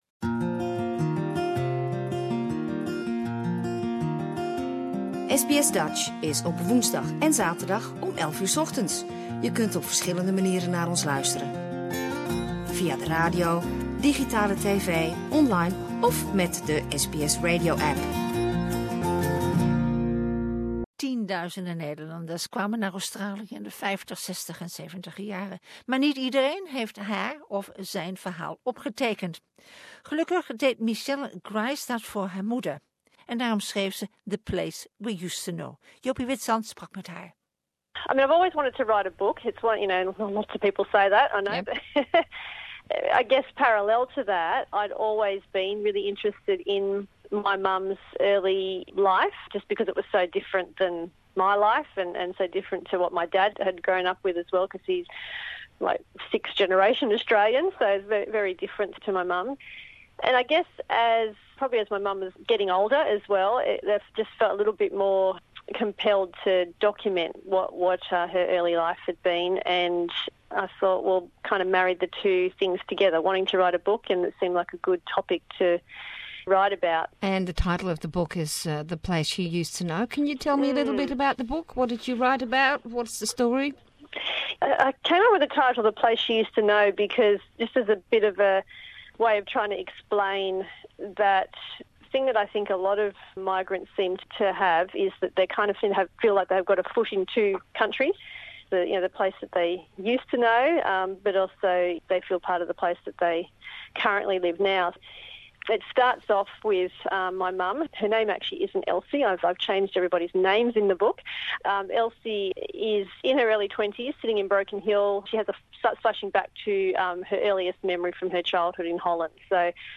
(Interview in English)